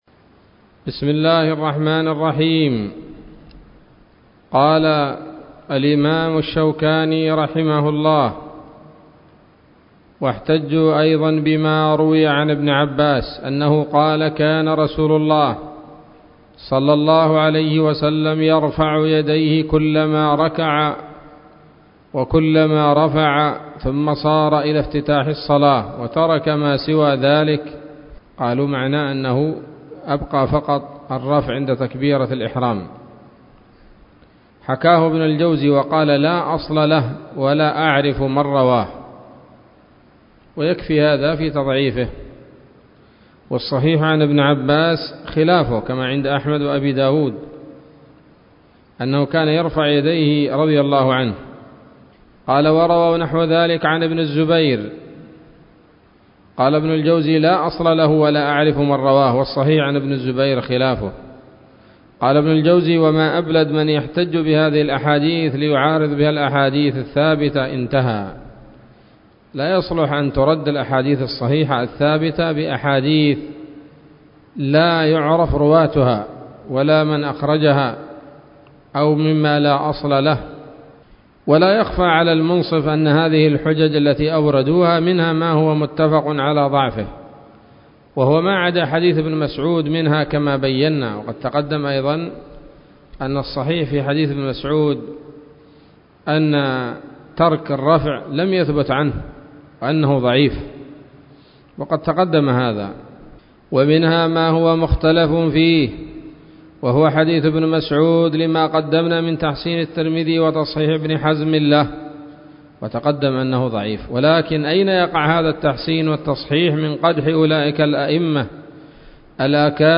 الدرس السابع من أبواب صفة الصلاة من نيل الأوطار